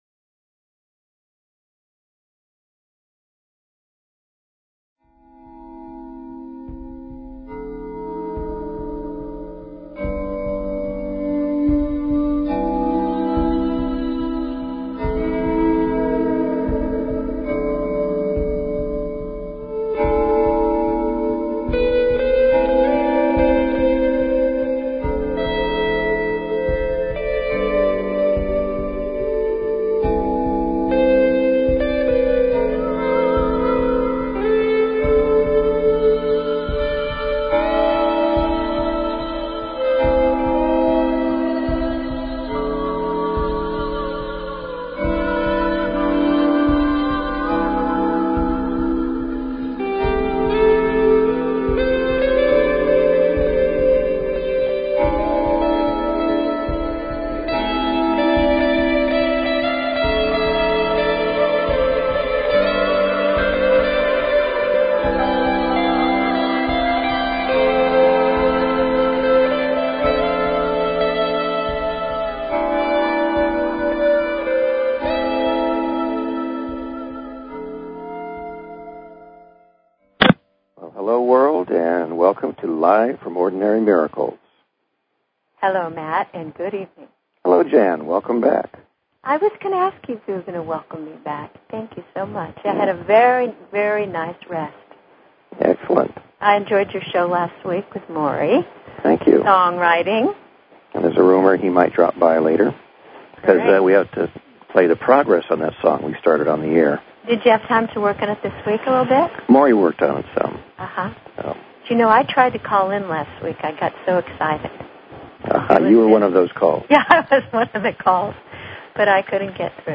Talk Show Episode, Audio Podcast, Live_from_Ordinary_Miracles and Courtesy of BBS Radio on , show guests , about , categorized as
Join us and our guests, leaders in alternative Health Modalities and Sound Healing, every Wednesday evening at the Ordinary Miracles Store in Cotati,California. We love call in questions!!!